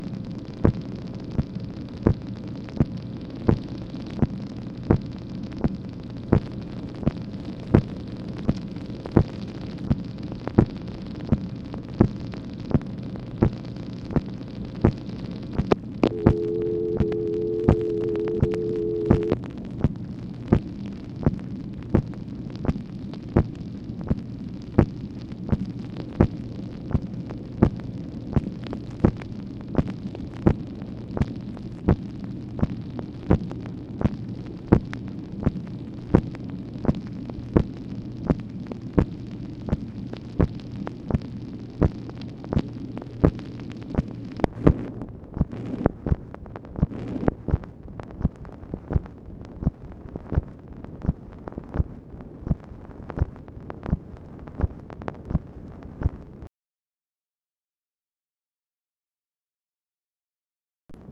MACHINE NOISE, June 9, 1965
Secret White House Tapes | Lyndon B. Johnson Presidency